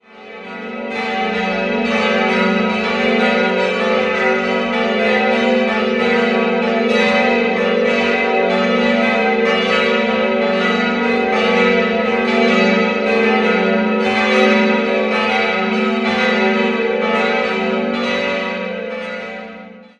Erst danach brachte die Reformation eine neue Ordnung der kirchlichen Verwaltung und eine Einteilung kirchlicher Aufsichtsbezirke. 4-stimmiges ausgefülltes G-Dur-Geläute: g'-a'-h'-d'' Die vier Glocken wurden im Jahr 2008 von der Firma Grassmayr in Innsbruck gegossen.